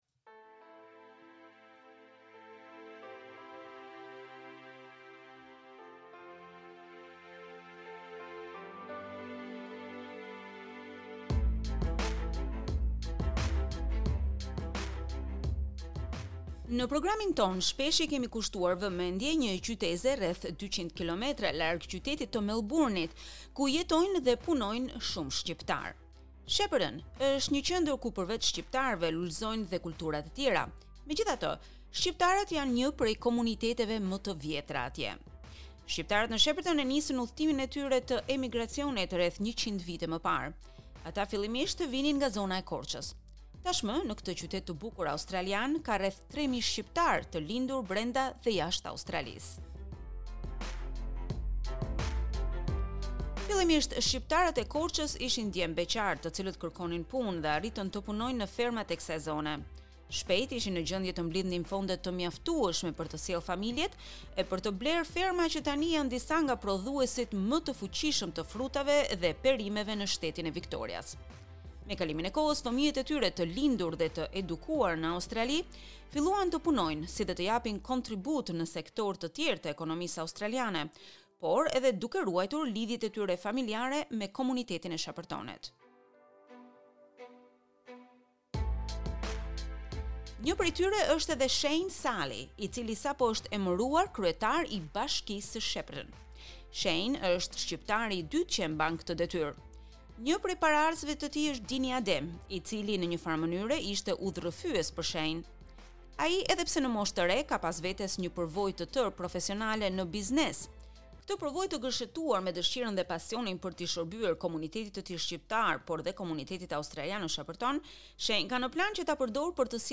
Intervistë me Shane Sali Në programin tonë shpesh i kemi kushtuar vëmendje një qyteze rreth 200 km larg qytetit të Melburnit ku jetojnë dhe punojnë shumë shqiptarë.